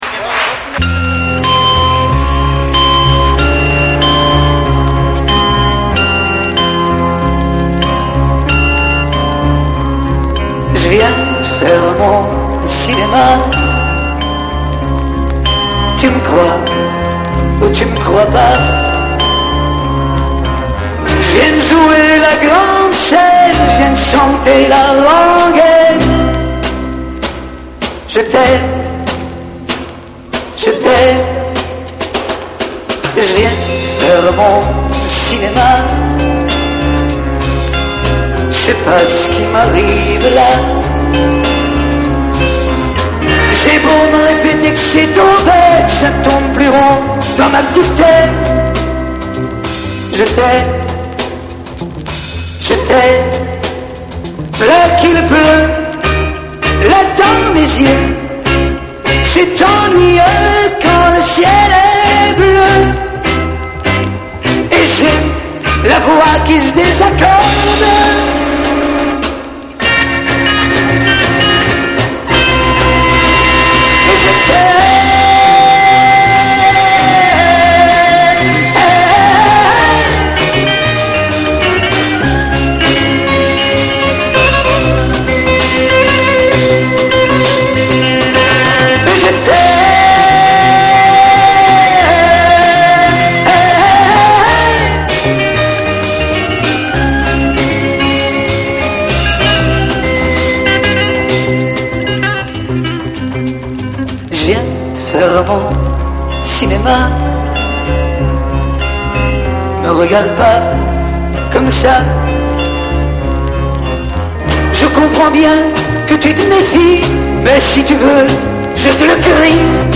Запись с концерта на фестивале “Золотой Орфей 1972″
Сопровождающий оркестр